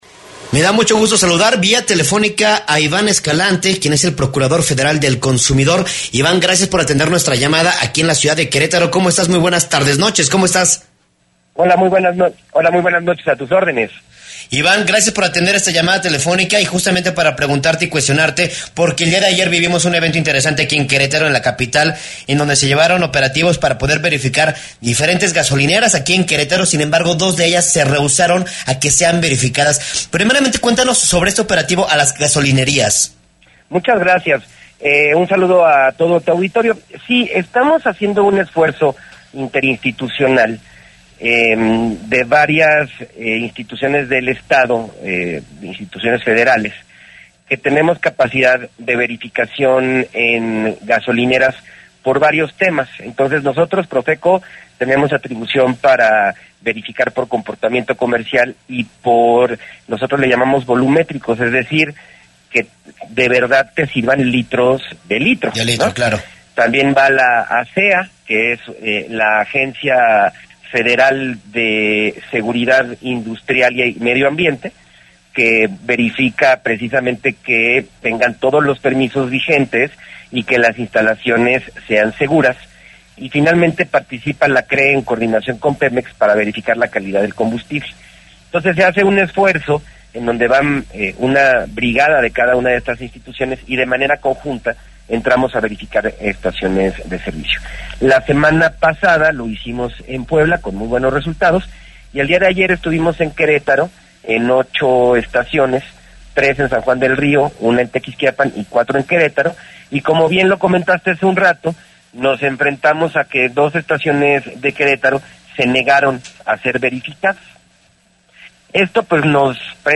EntrevistasPodcast